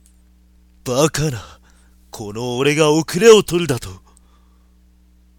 RPG戦闘終了後キャラクター台詞です。
作った当時に出していた声を忘れたので、2通り録ってみました。
しっかし、マイクの集音力高すぎです。
マウスのクリック音まで入ってますよ。